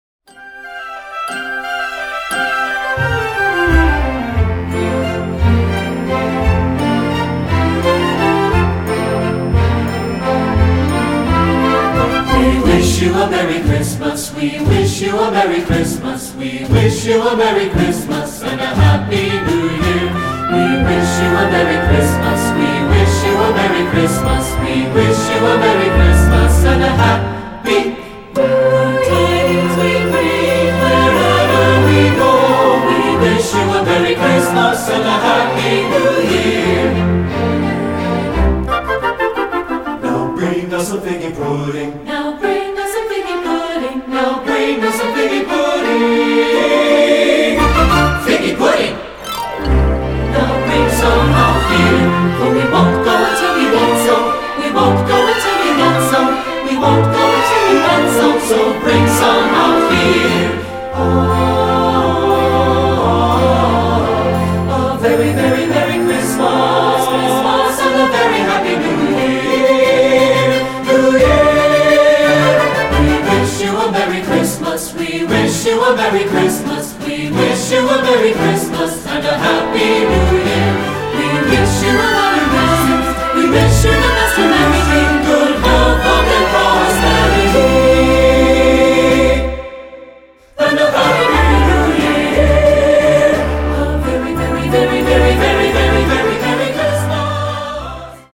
Choral Christmas/Hanukkah Women's Chorus
English Carol
SSA